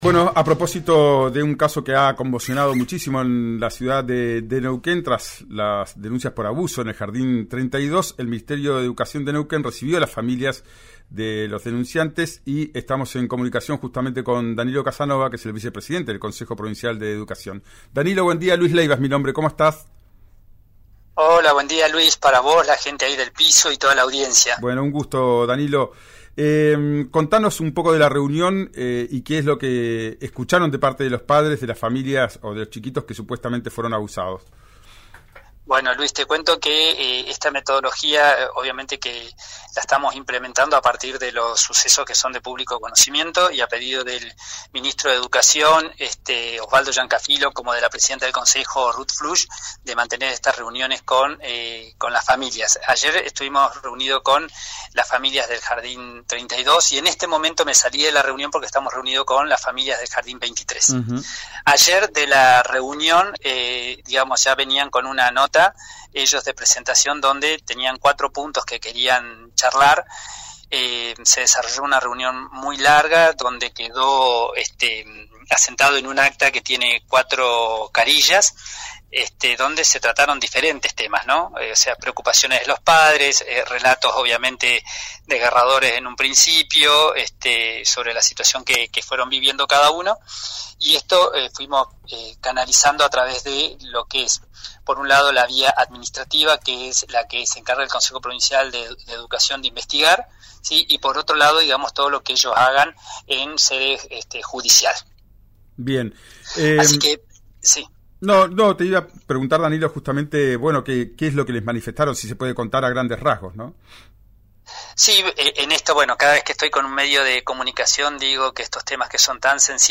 El vicepresidente del consejo, Danilo Casanova, en diálogo con RÍO NEGRO RADIO contó cómo fue el encuentro que se llevó a cabo este miércoles. Explicó que los padres asistieron con una nota donde exponían cuatro puntos a tratar los cuales aseguró, fueron tomados en cuenta y trasladados a un acta.